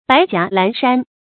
白袷藍衫 注音： ㄅㄞˊ ㄐㄧㄚˊ ㄌㄢˊ ㄕㄢ 讀音讀法： 意思解釋： 舊時士人的服裝。亦借指尚未取得功名的士人。